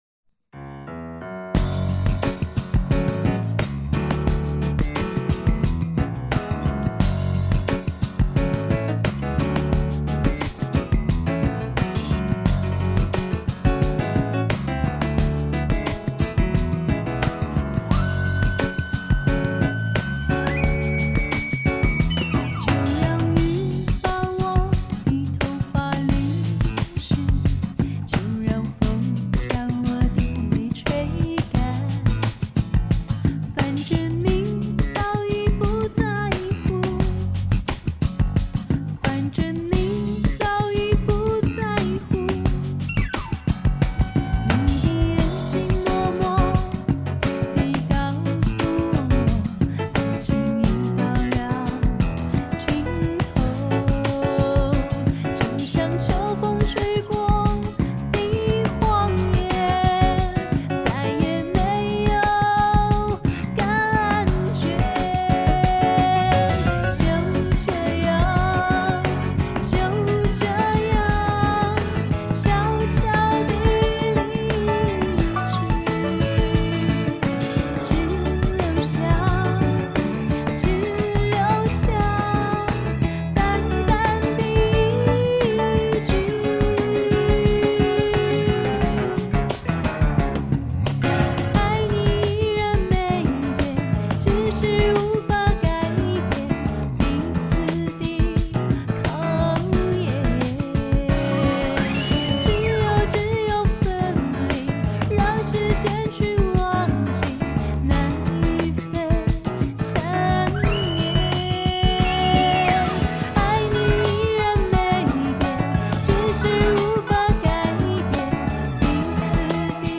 世界顶级录音设备全面满足听觉享受
天籁女声无法抗拒
而配乐中箫，琵琶，二胡，笛子，扬琴，木吉他的编排演奏，
音乐实在出采，贝司，鼓和电钢琴配合美妙绝伦，
琵琶画龙点睛，说出爱情是不可以拿来考验的。